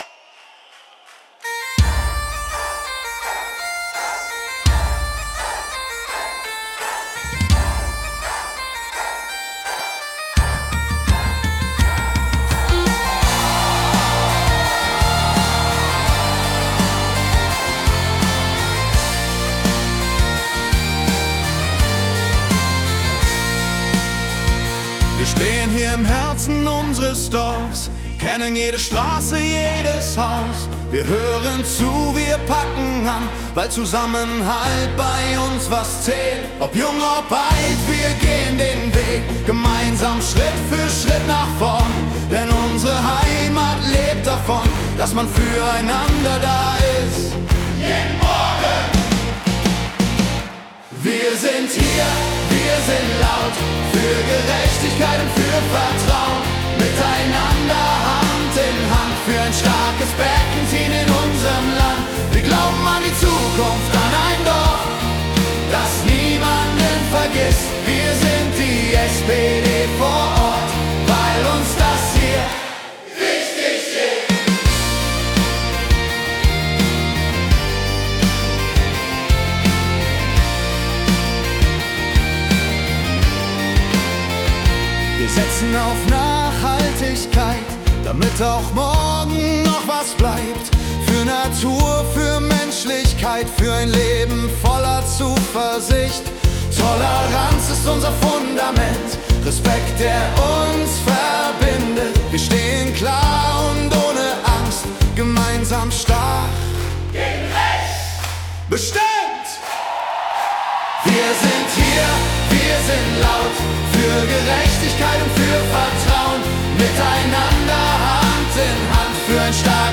KI-generiert
Suno AI (c)